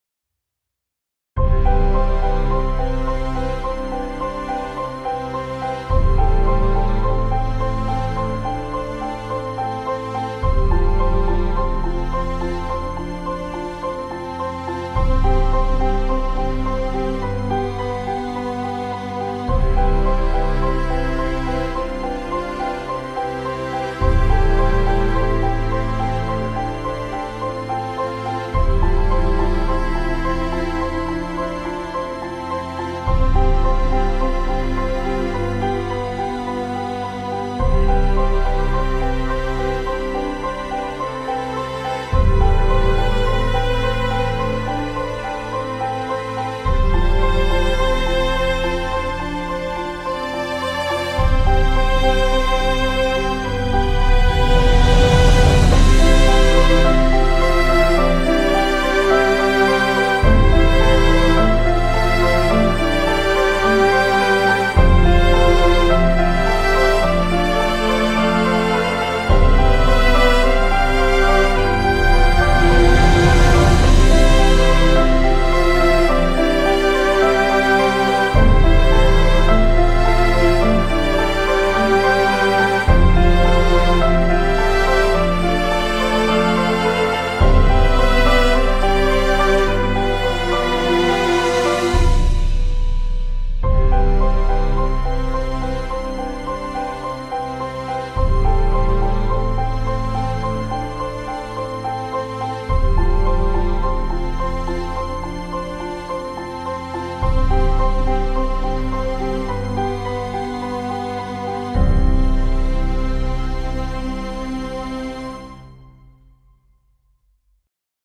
Música-Emocional-e-Inspiradora.mp3